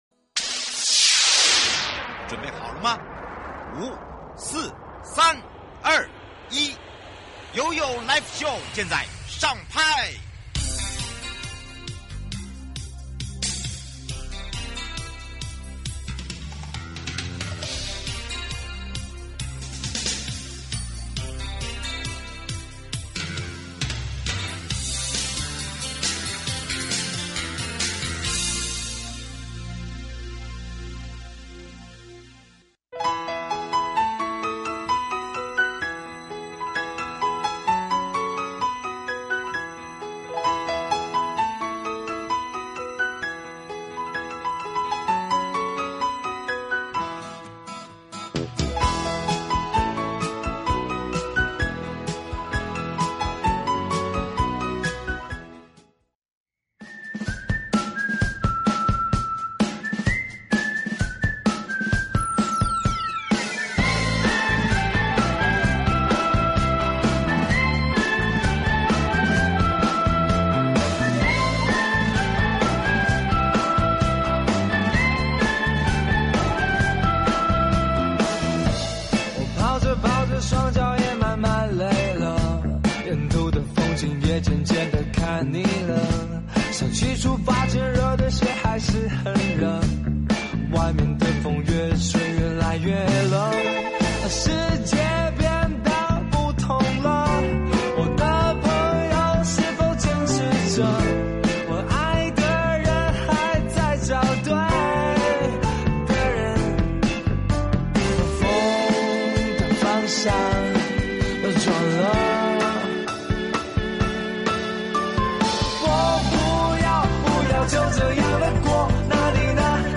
受訪者： 1.東北角管理處